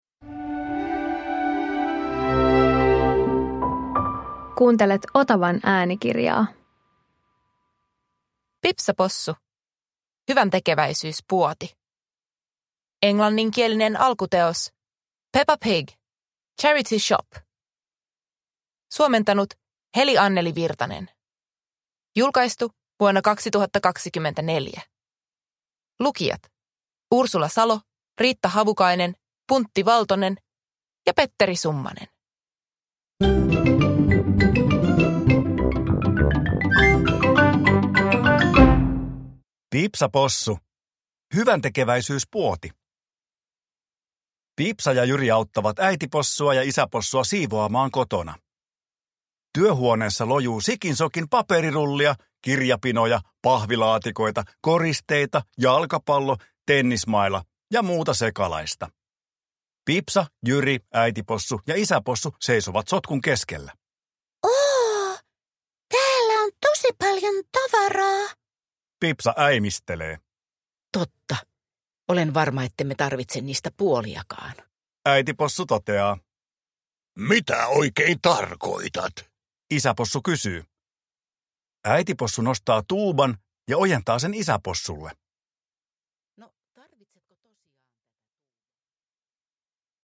Pipsa Possu - Hyväntekeväisyyspuoti – Ljudbok